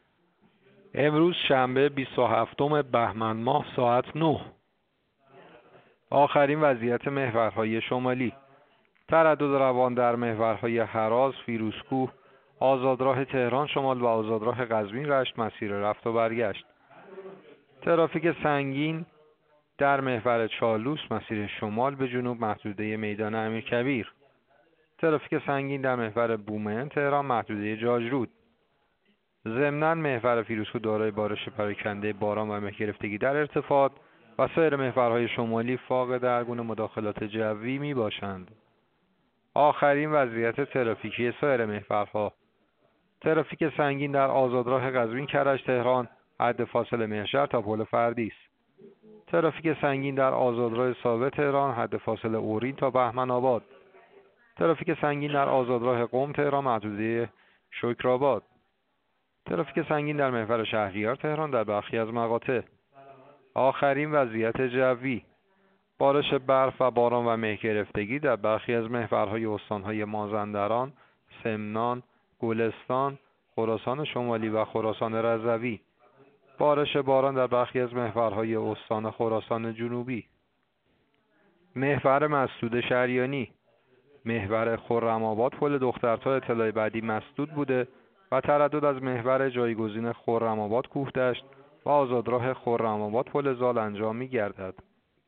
گزارش رادیو اینترنتی از آخرین وضعیت ترافیکی جاده‌ها ساعت ۹ بیست و هفتم بهمن؛